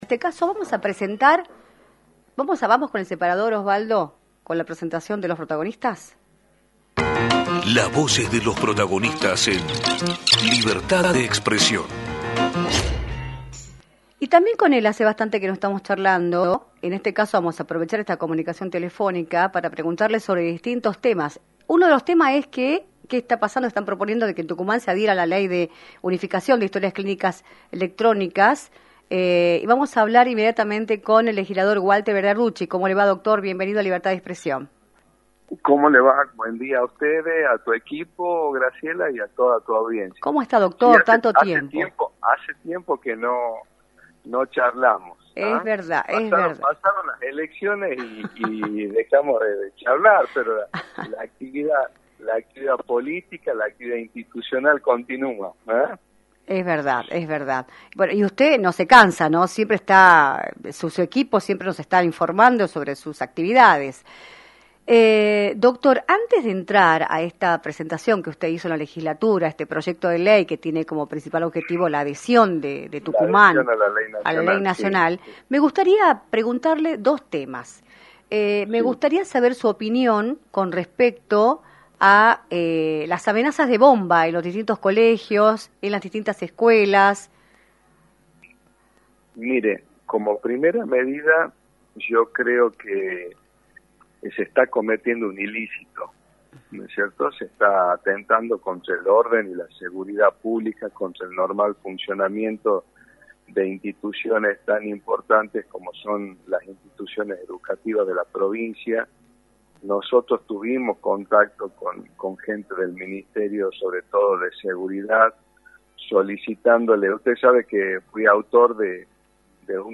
Walter Berarducci, Legislador, analizó en “Libertad de Expresión”, por la 106.9, la situación social de la provincia, luego de las amenazas de bomba que están sucediendo en Tucumán desde hace semanas y sobre el proyecto de ley que busca la adhesión a la Ley Nacional por la cual se digitalizan las historias clínicas de los […]